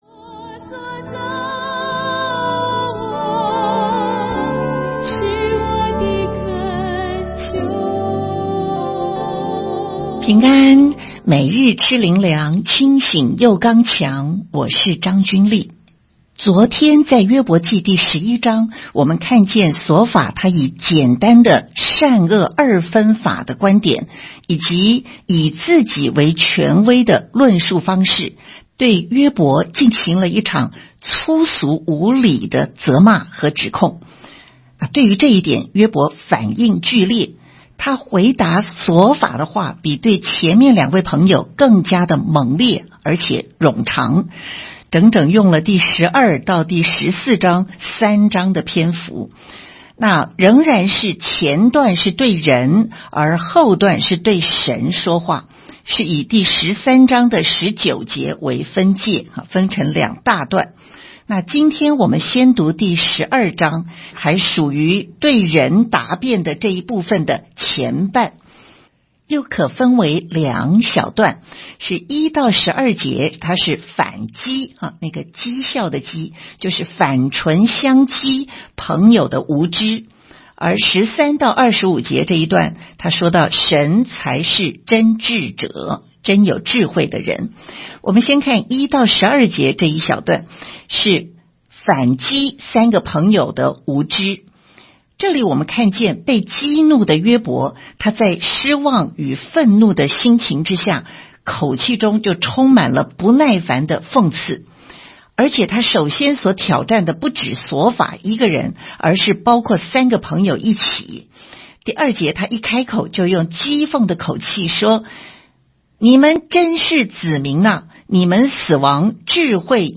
12/22 每日灵修｜约伯记-约伯对琐法的反驳 (补12)